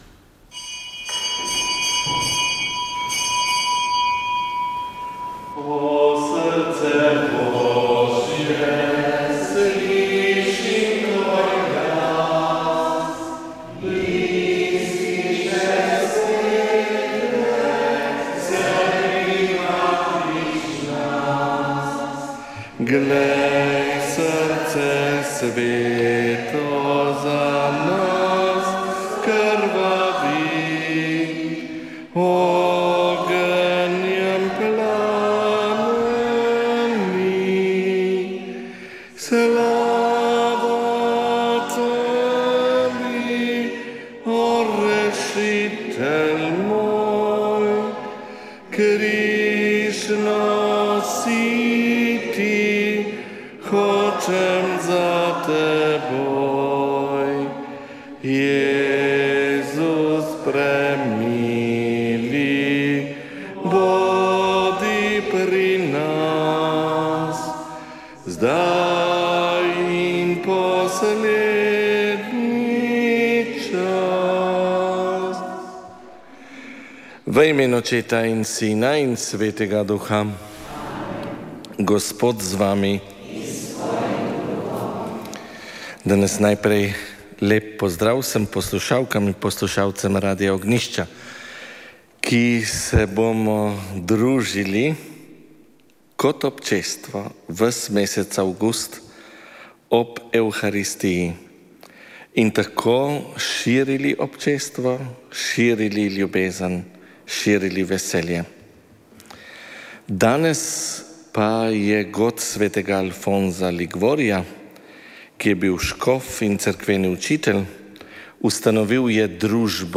Sv. maša iz stolne cerkve sv. Nikolaja v Murski Soboti 31. 7.